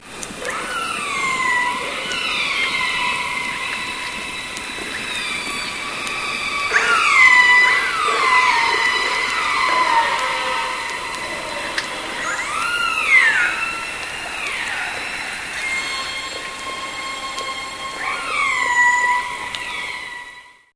Звук косатки вдали